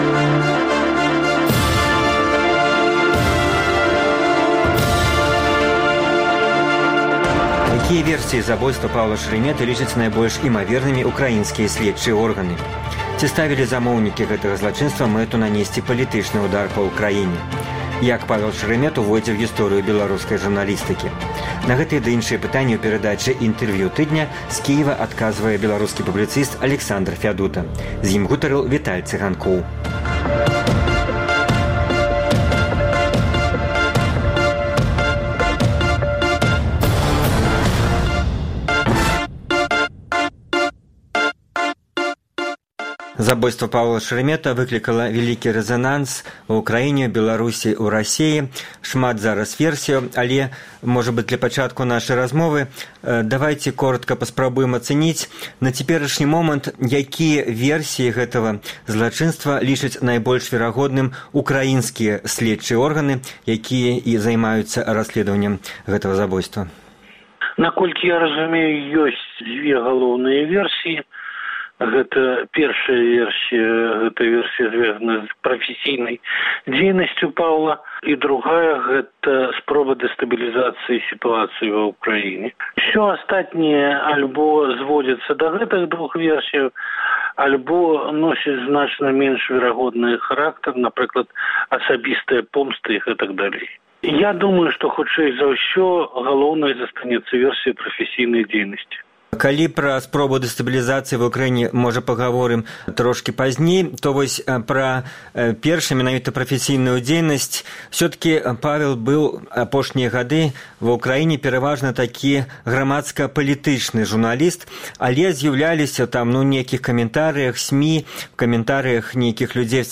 «Інтэрвію тыдня»